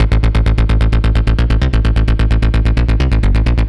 Index of /musicradar/80s-heat-samples/130bpm
AM_OB-Bass_130-A.wav